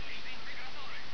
Type: Sound Effect